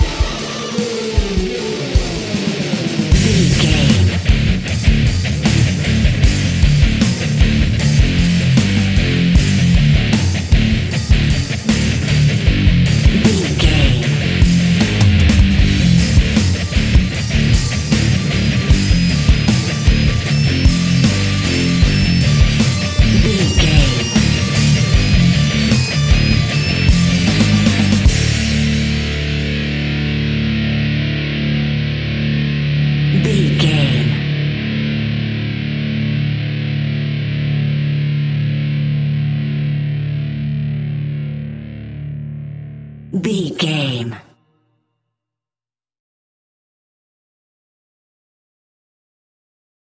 Powerful Rock Metal Death 30 Sec.
Epic / Action
Fast paced
Aeolian/Minor
heavy metal
Rock Bass
heavy drums
distorted guitars
hammond organ